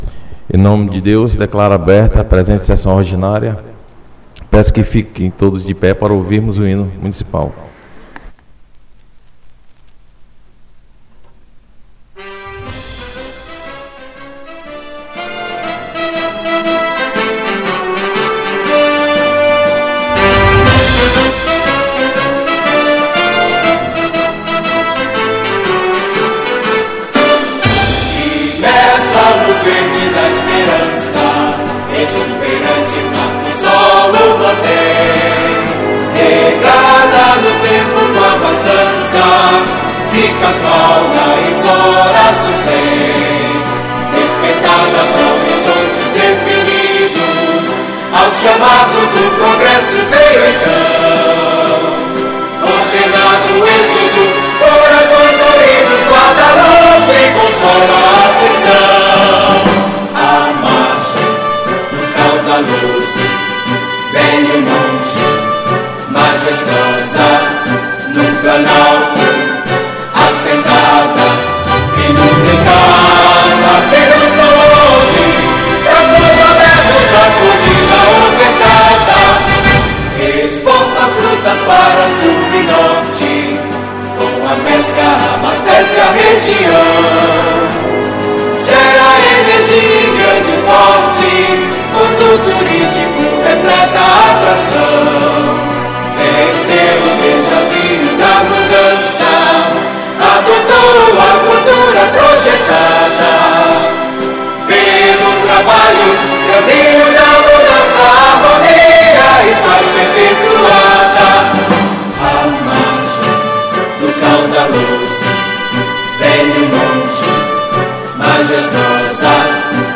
31 - Sessão Ordinária 15.12.2022